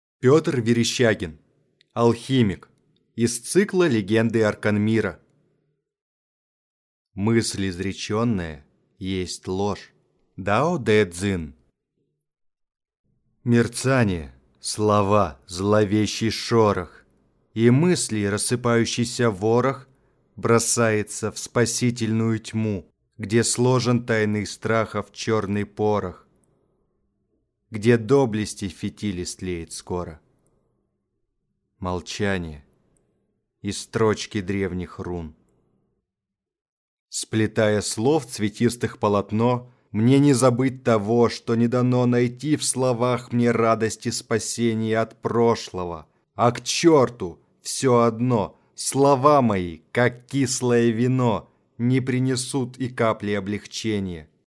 Аудиокнига Алхимик | Библиотека аудиокниг